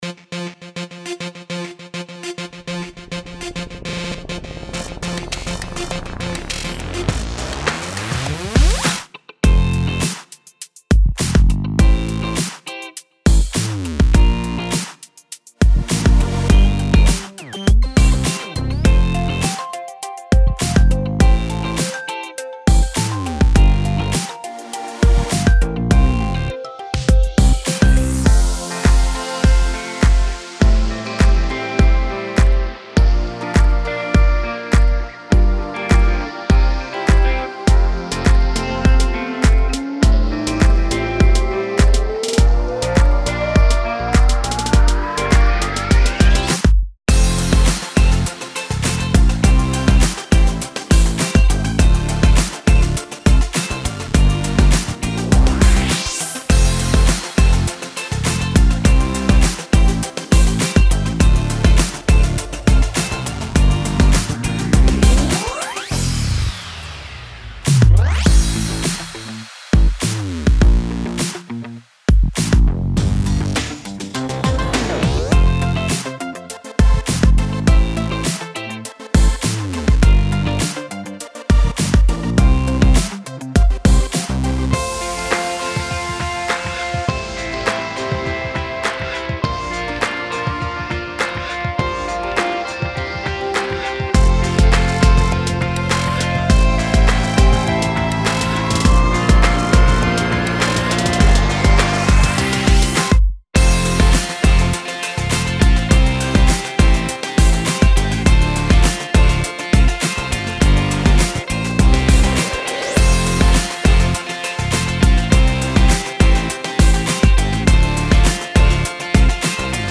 Яркая современная детская песня.
Характер песни: весёлый.
Темп песни: быстрый.
• Минусовка
в оригинальной тональности Фа мажор